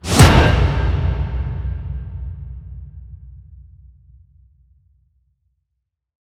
SFX噔3 000音效下载
SFX音效